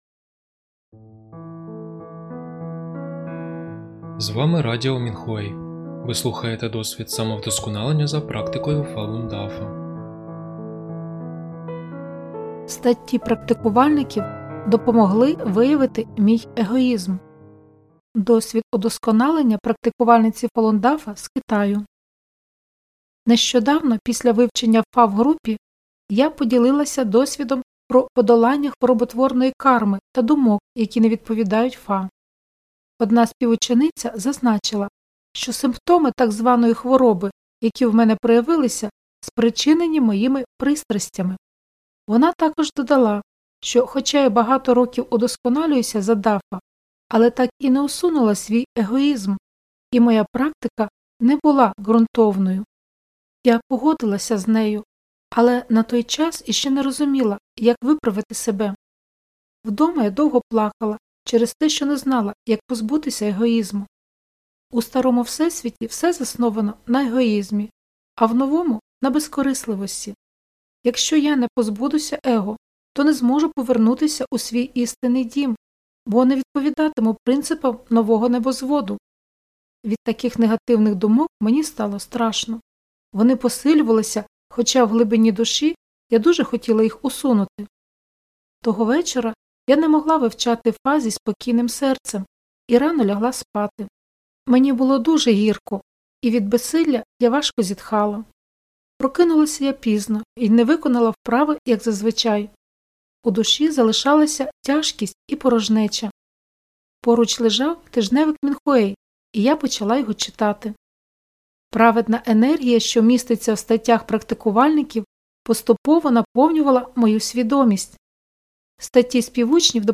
Музика з подкастів написана та виконана учнями Фалунь Дафа.